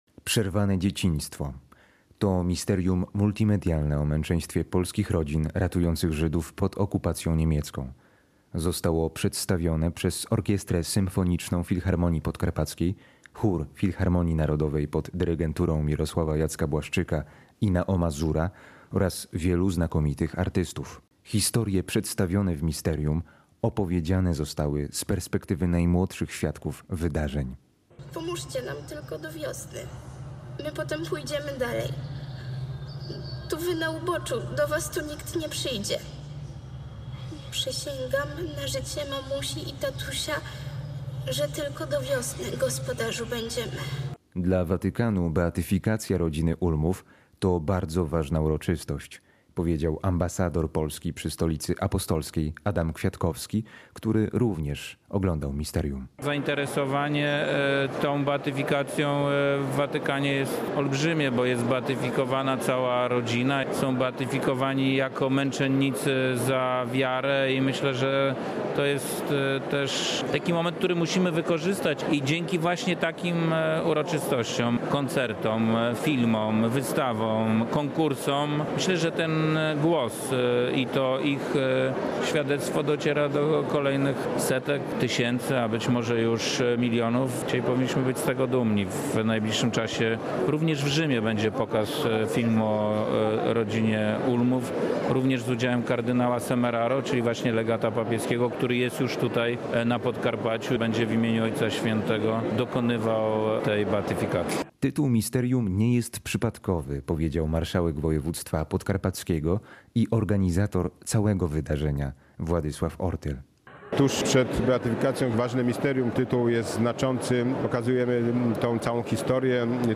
W sobotę wieczorem w Filharmonii Podkarpackiej w Rzeszowie prezydent Andrzej Duda wraz z małżonką Agatą Kornhauser – Dudą obejrzeli misterium multimedialne „Przerwane dzieciństwo”, stworzone dla uczczenia pamięci rodziny Ulmów z Markowej.
W trakcie misterium przeplatały się utwory powstałe na bazie biblijnych tekstów psalmów oraz wątków zaczerpniętych z muzyki żydowskiej.